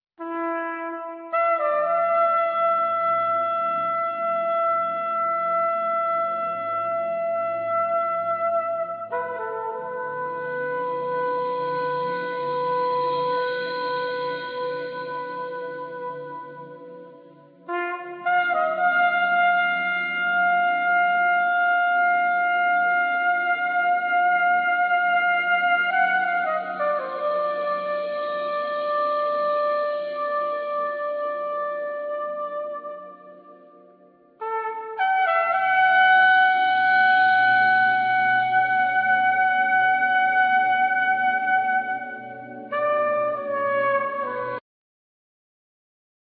trumpet, flugelhorn
acoustic bass
tenor and soprano saxophone
drums and percussion
synthesizers, piano